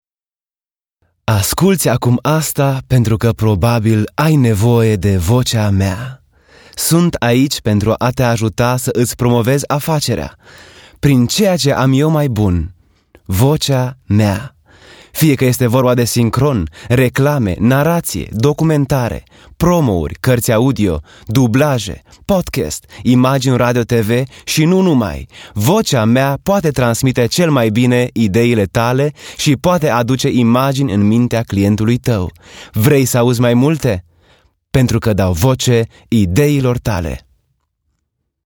dynamisch, frisch, mittelkräftig, seriös
Sprechprobe: Sonstiges (Muttersprache):